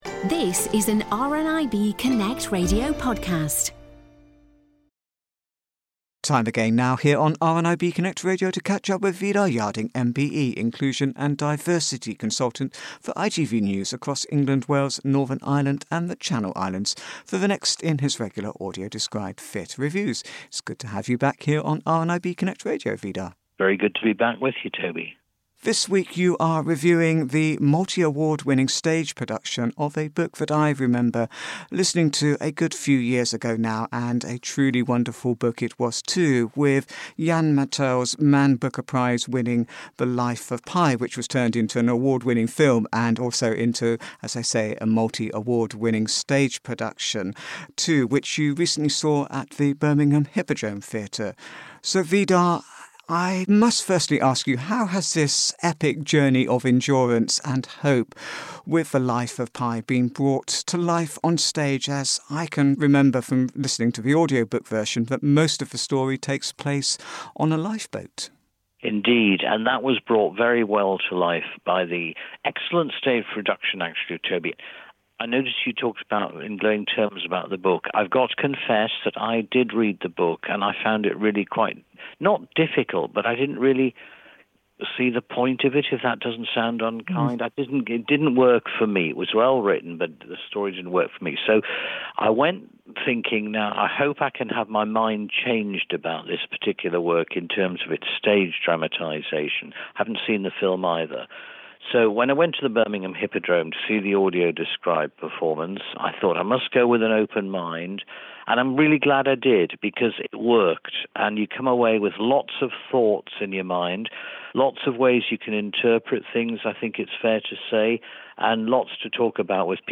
AD Theatre Review